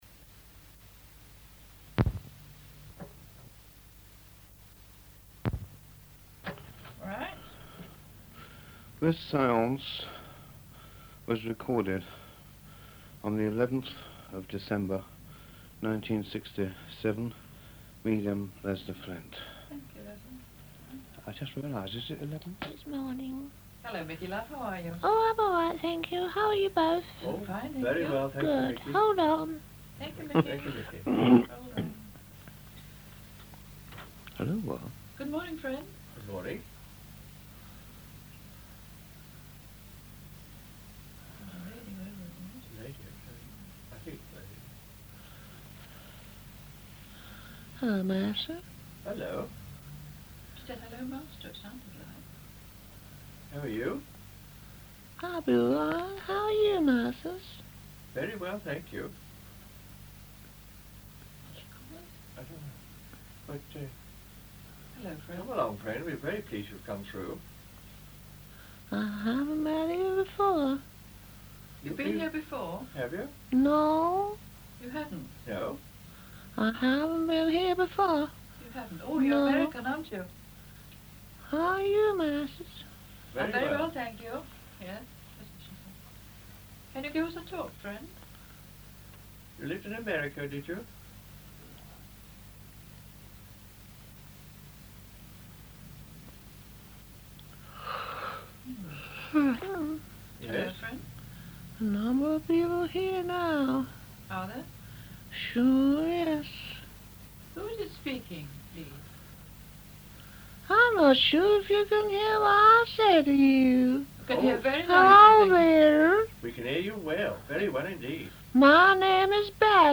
The former Queen Alexandria (of Denmark December 1 1844, passed November 20, 1925 - Source: Wikipedia ) then tries to start speaking, at first with some hesitation, yet later manages to speak a long and beautiful discussion regarding why we can no longer live with the historic barriers humans have created, and that we must now live together in peace because there is now no escape if a nuclear war takes place.